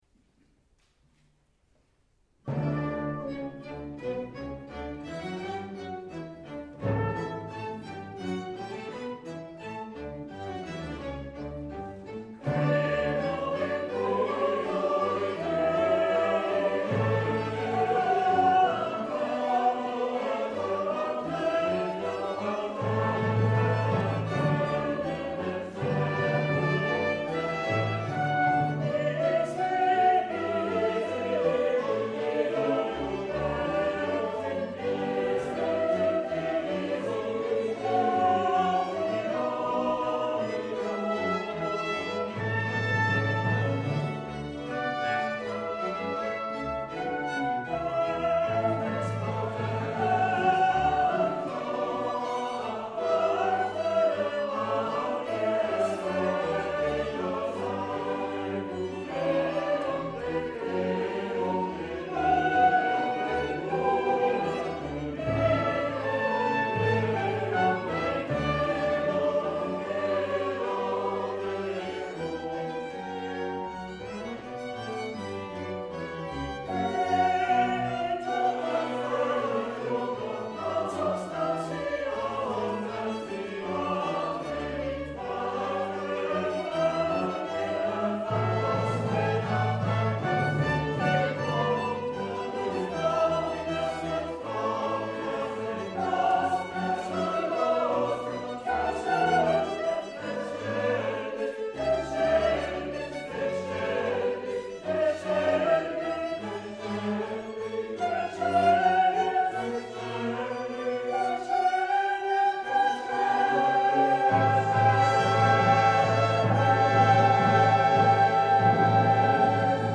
Concert de l’Avent (Credo)